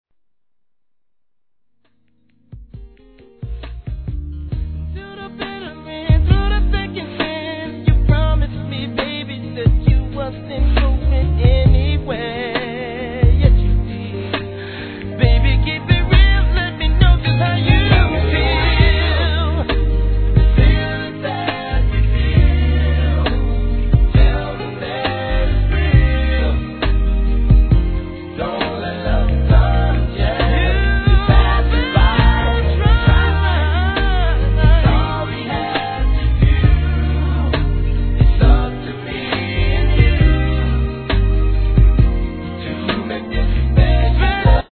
HIP HOP/R&B
発売時期である11月〜クリスマスにかけて必須のミディアム、スロウバラード。